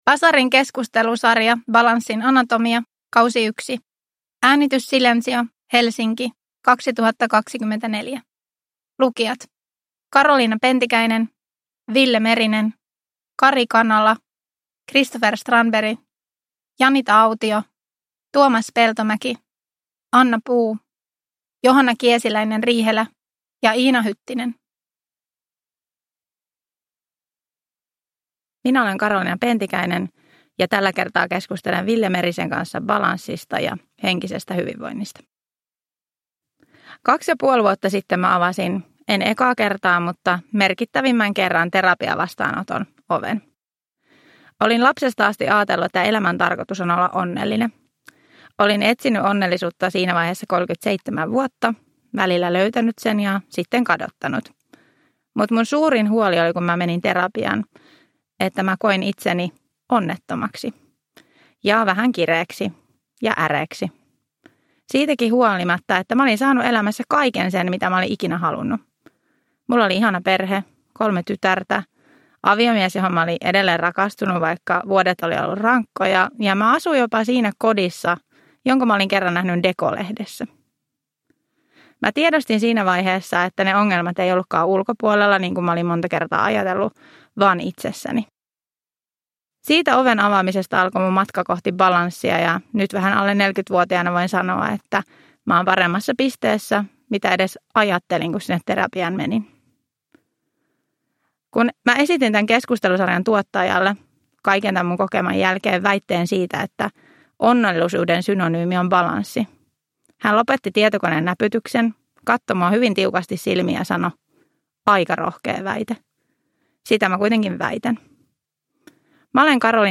Lämminhenkistä keskustelua elämän tasapainosta